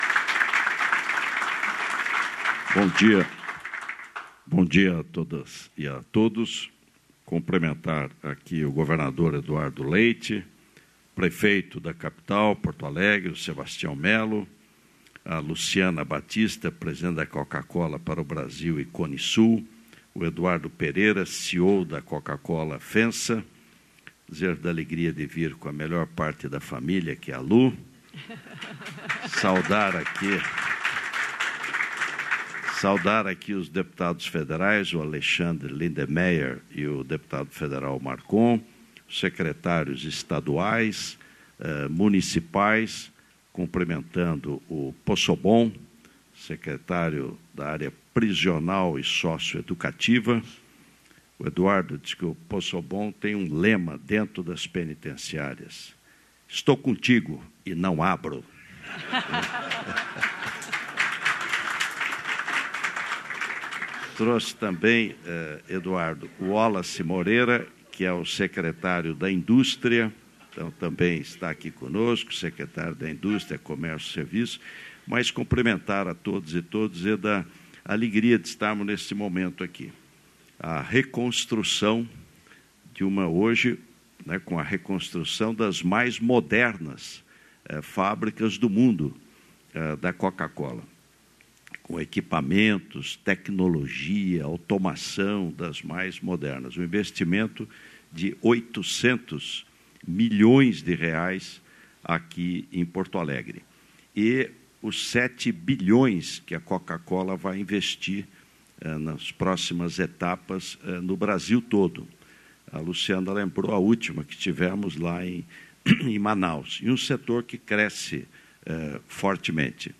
Íntegra dos discursos dos ministros, Renan Filho (Transportes) e Fernando Haddad (Fazenda), em visita às obras da Rodovia Presidente Dutra, na Serra das Araras-RJ, nesta terça-feira (15), em Paracambi, no Rio de Janeiro.